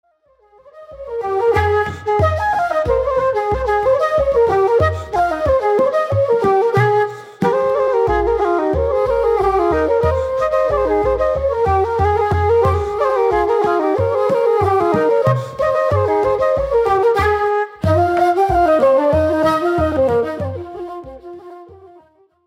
Flutes D, Eb, C, Bb - Low whistles F, D
Guitars
Fiddle, Piano & Harmonium
Wire Strung Harp
Double Bass
Bodhrán